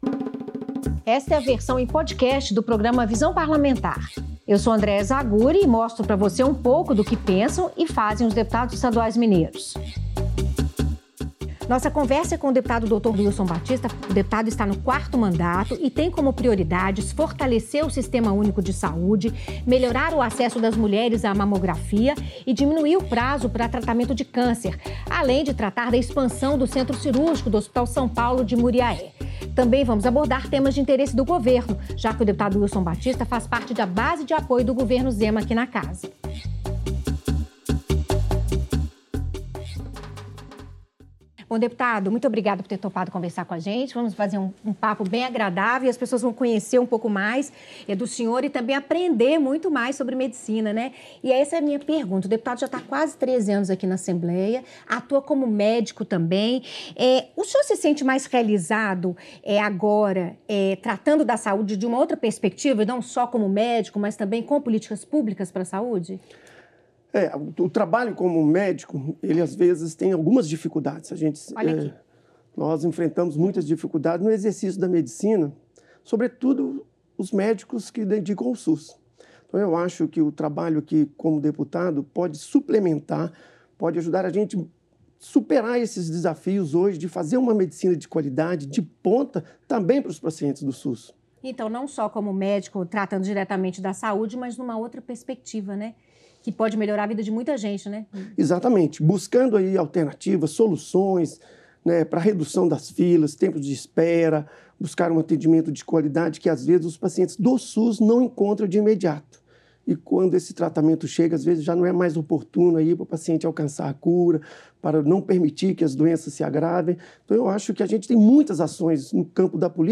O deputado Doutor Wilson Batista (PSD) é o entrevistado da semana no programa Visão Parlamentar. Entre os vários assuntos, ele falou sobre a situação do SUS no Estado, a gestão da saúde pública e principalmente sobre a defesa da importância da mamografia como detecção precoce do câncer de mama.